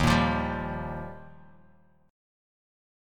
D#add9 chord